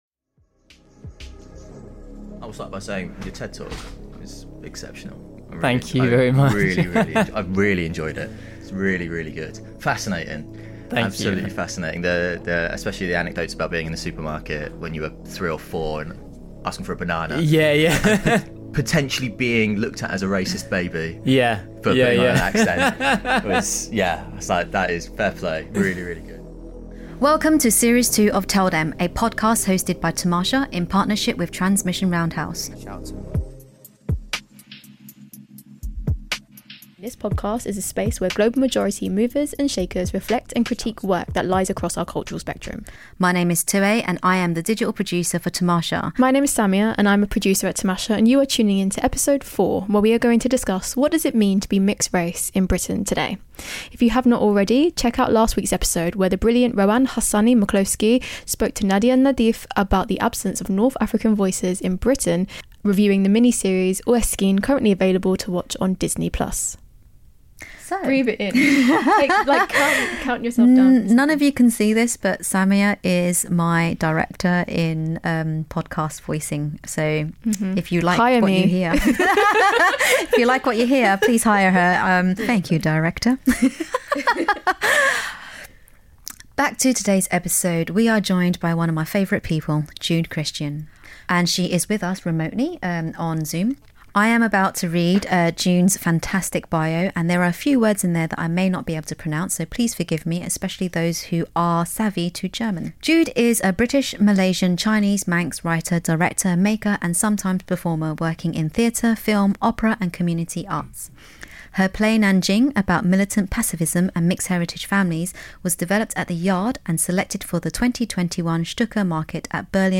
She’s joined by actor Jassa Ahluwalia and Blue Peter presenter, Richie Driss. The trio explore what it means to identify as Mixed Race in Britian today, with personal and professional experiences and how those experiences have shaped them today.